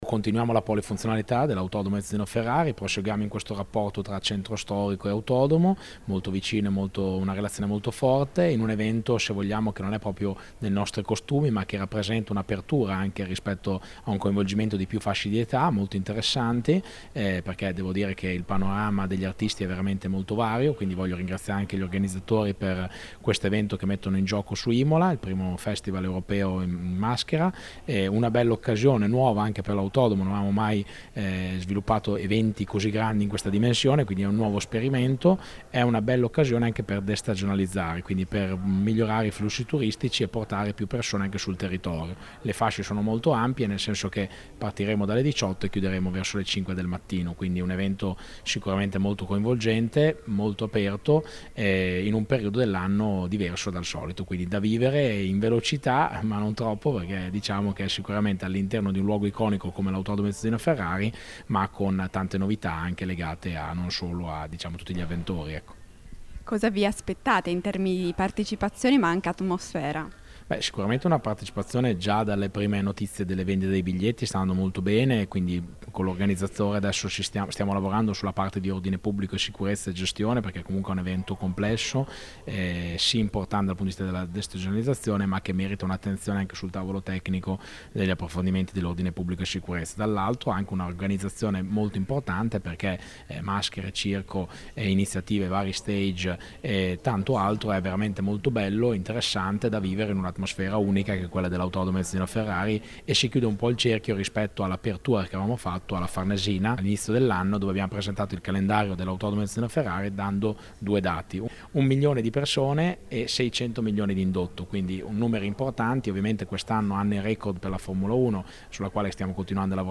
Ascolta l’intervista a Marco Panieri, Sindaco Imola:
Marco-Panieri-Sindaco-Imola.mp3